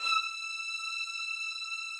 strings_076.wav